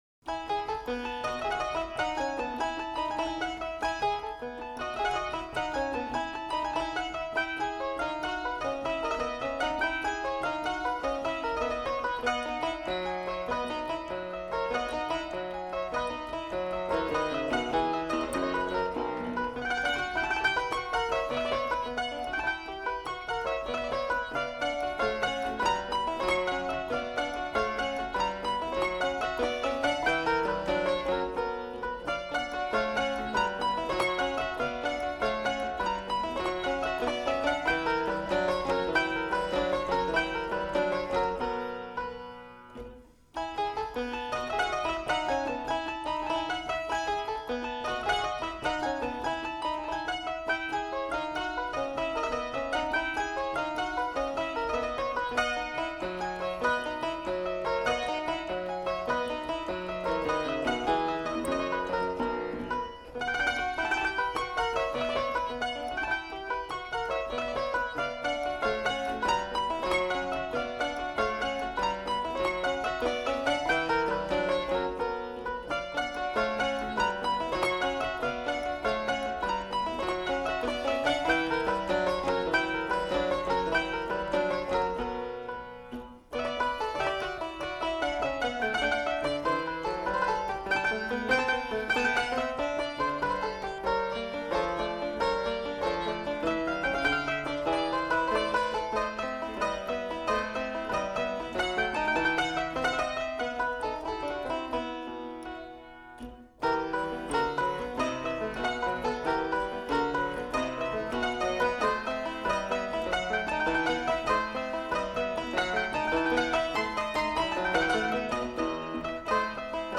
ENGLISH PIANO - The Cobbe Collection
by Johannes Zumpe and Gabriel Buntebart, London, 1769